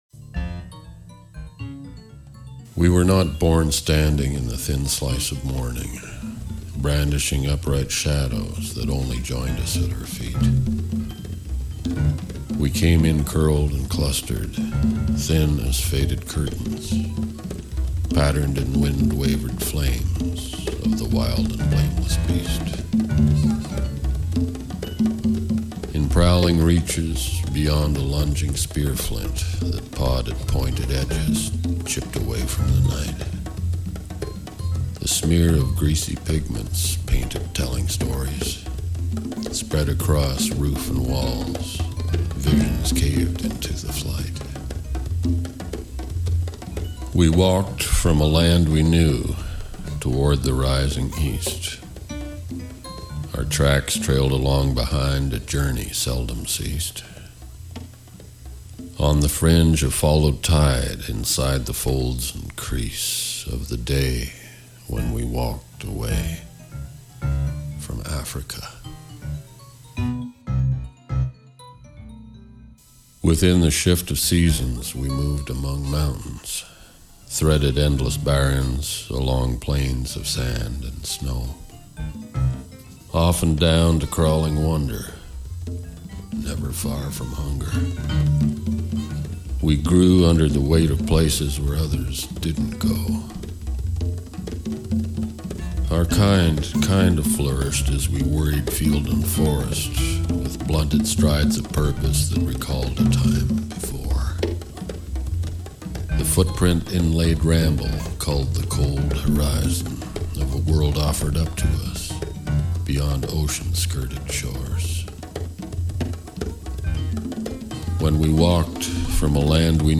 short poem readings, mixed with composed music and sound backgrounds and interludes